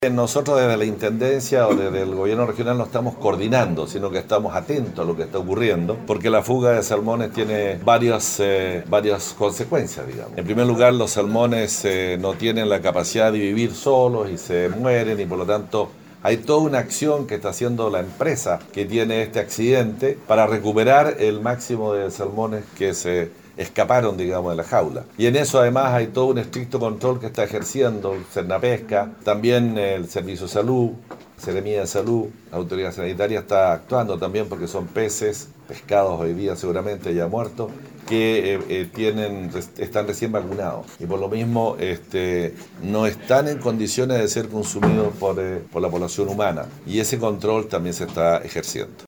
Intendente se refiere a fuga de salmones
10-INTENDENTE-SALMONES.mp3